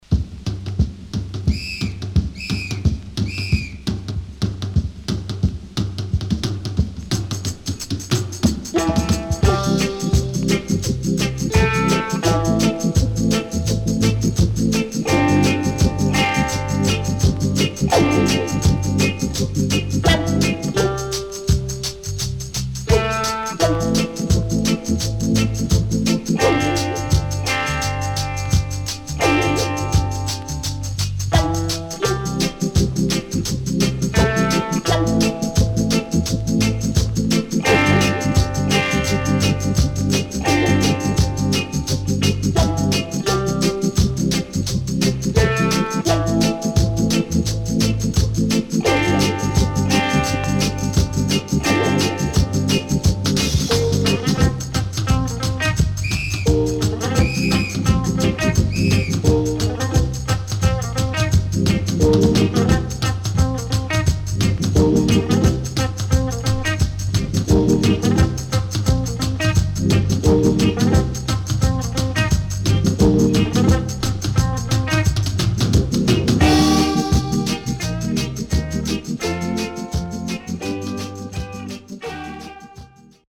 FUNKY REGGAE
SIDE A:少しチリノイズ入りますが良好です。